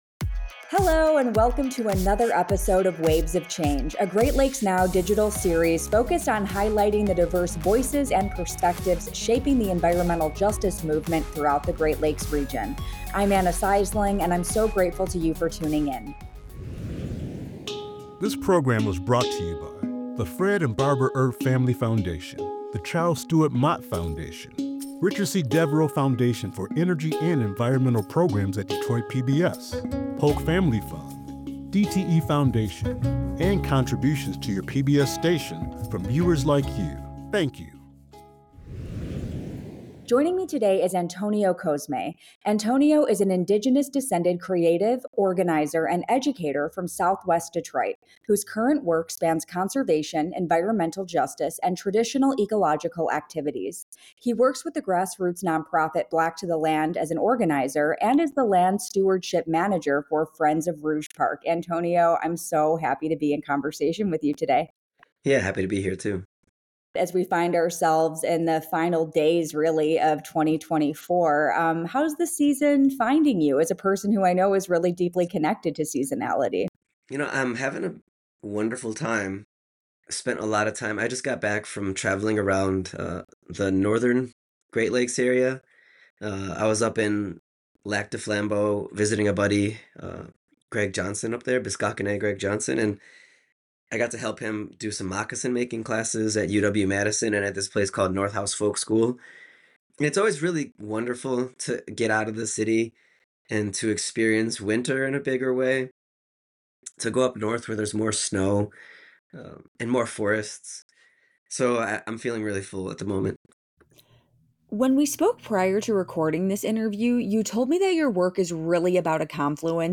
Waves of Change is an online interview series highlighting the diverse faces and perspectives shaping the environmental justice movement throughout the Great Lakes region.